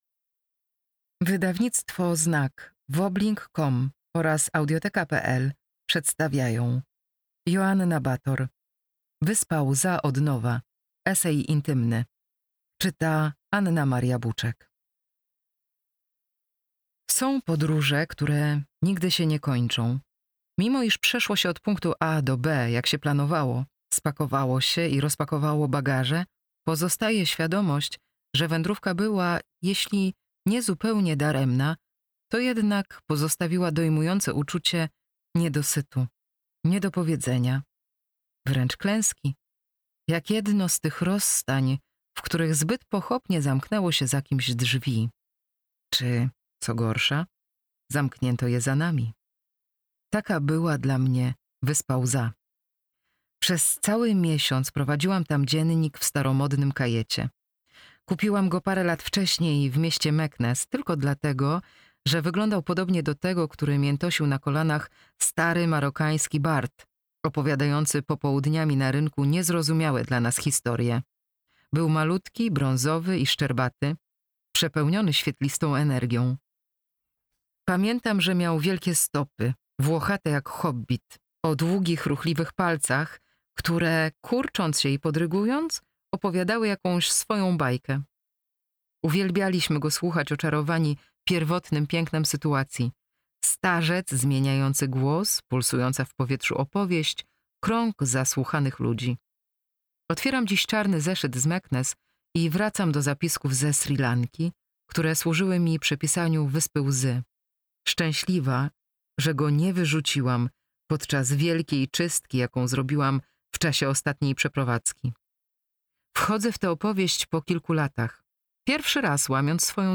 Audiobook + książka Wyspa Łza od nowa, Joanna Bator.